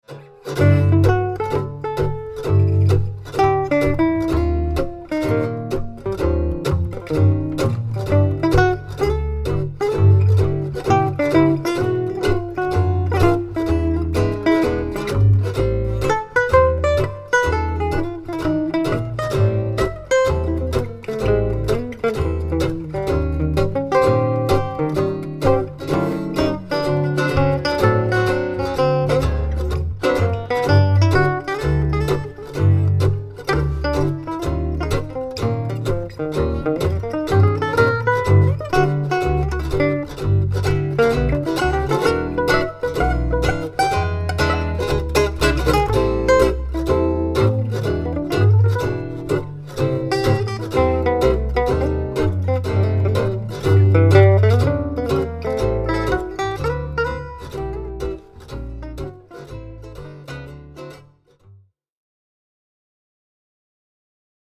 Tone is a little banjoey.
mini-manouche-test.mp3